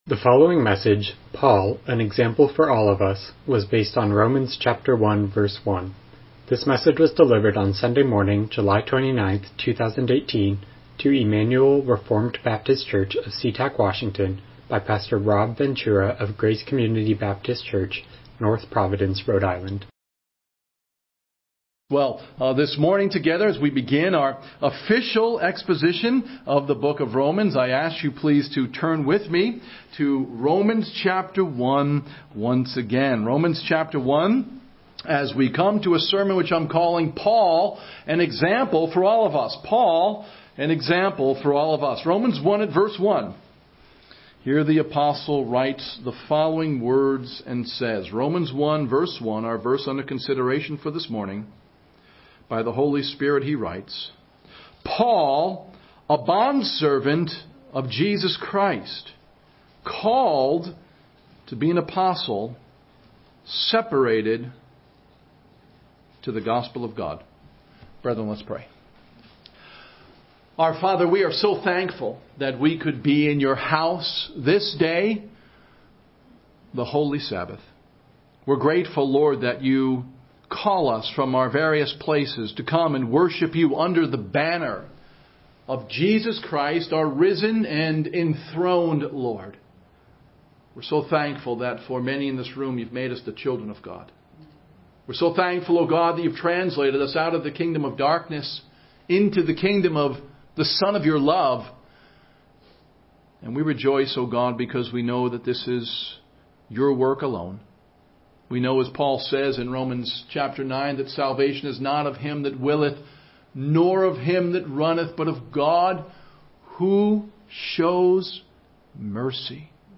Romans 1:1 Service Type: Morning Worship « Romans